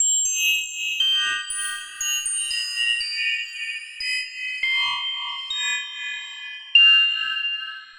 FM Destress Drops.wav